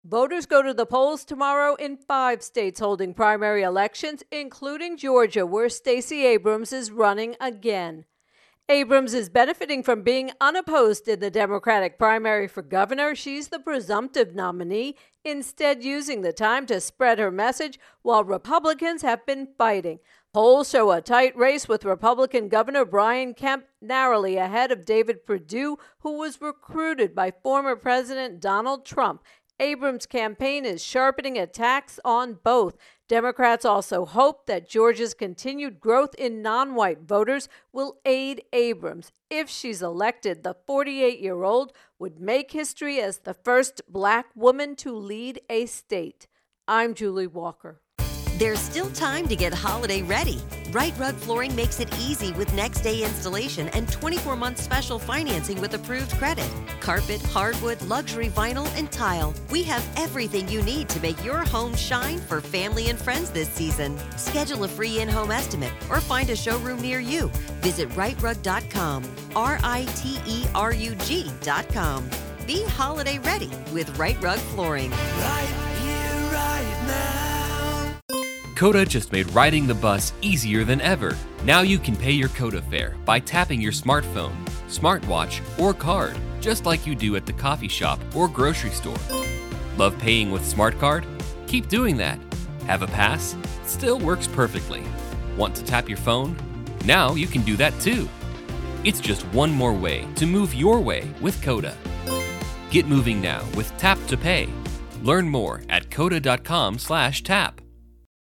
Election 2022 Georgia Abrams intro and voicer